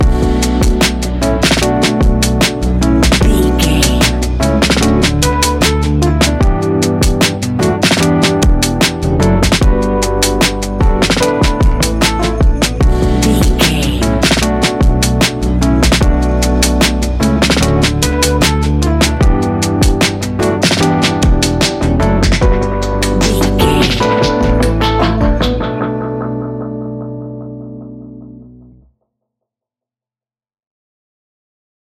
Ionian/Major
B♭
chilled
laid back
Lounge
sparse
new age
chilled electronica
ambient
atmospheric